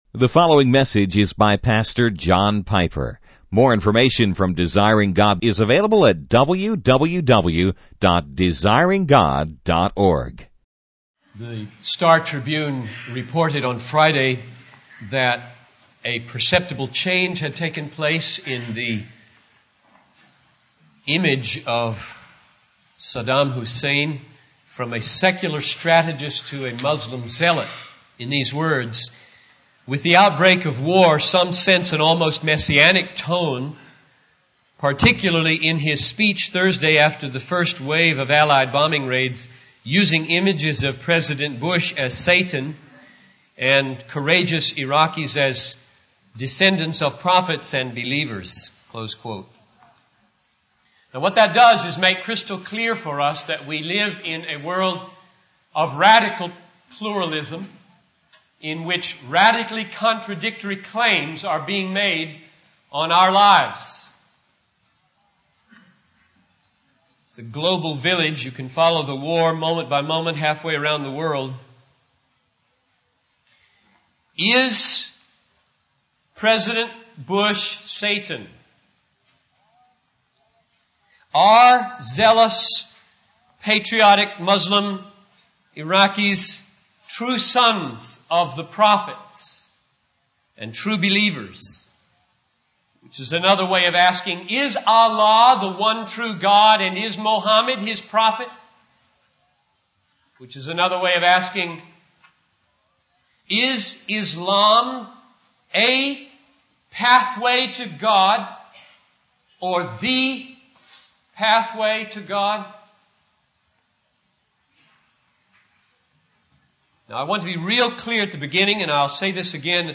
In this sermon, Pastor John Piper discusses the current state of the world, highlighting the radical pluralism and contradictory claims that exist in society. He emphasizes that we live in a world where Christianity and other traditional views are being pushed to the sidelines, as secularism becomes the new consensus. Piper warns that as believers, we must bear witness to the uniqueness and supremacy of Jesus Christ, even though it may become increasingly difficult and unsafe.